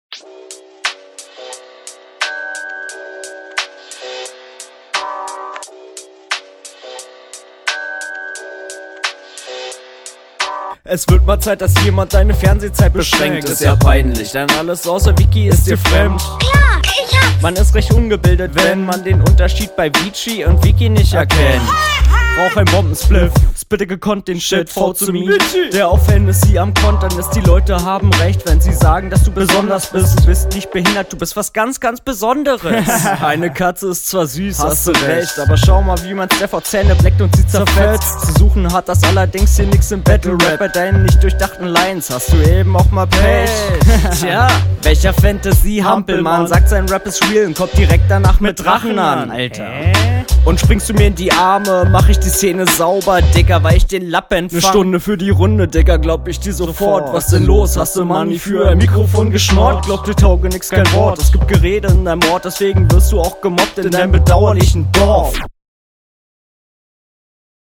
Wenn man erst die Runde vom Gegner hört, dann ist die Soundquality hier noch super …